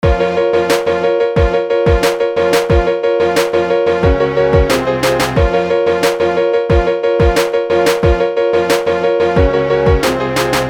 描述：一个带有紧张的鼓声、弦乐和钢琴和弦的循环。
Tag: 90 bpm Hip Hop Loops Groove Loops 1.79 MB wav Key : Unknown